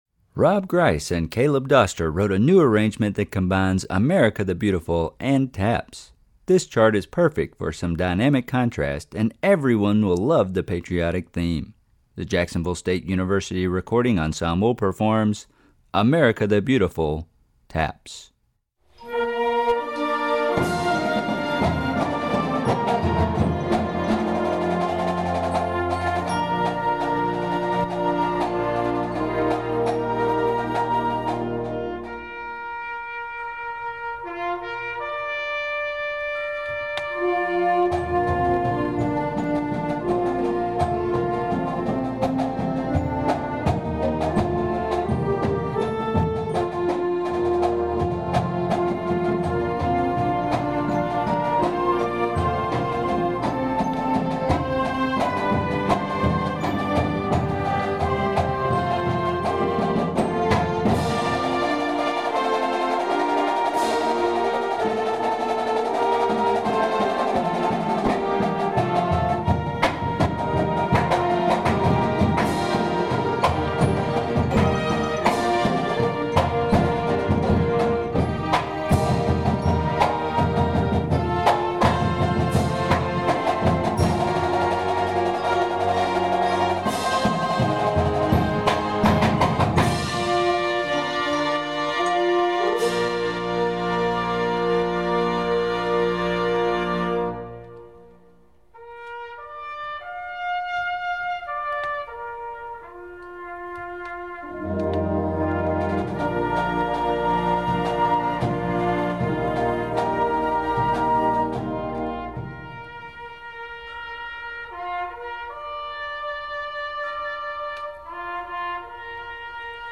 Processional Band Music